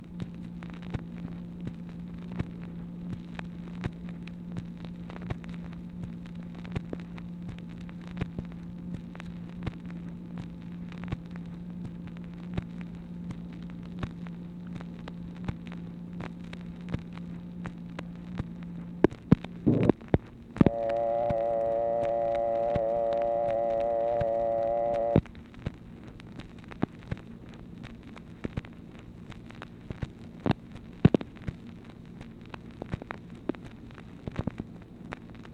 MACHINE NOISE, March 21, 1964
Secret White House Tapes | Lyndon B. Johnson Presidency